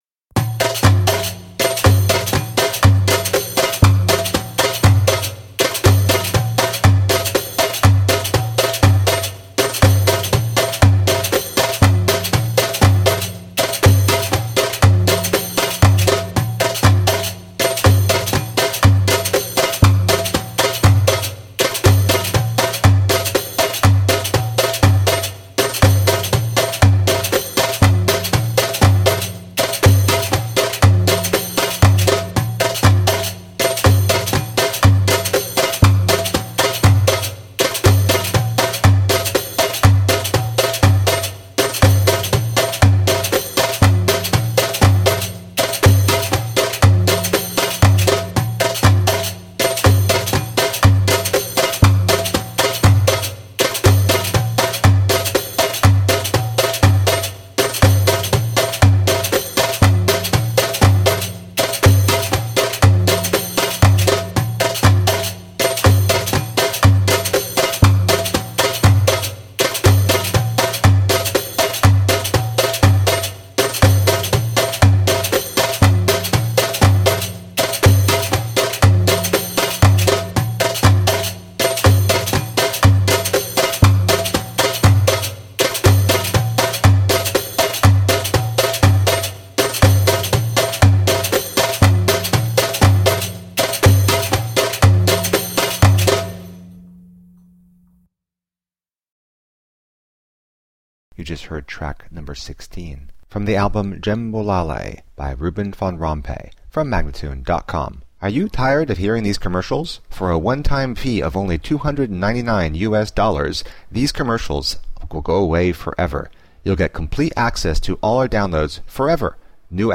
Passionate eastern percussion.
Tagged as: World, Loops, Arabic influenced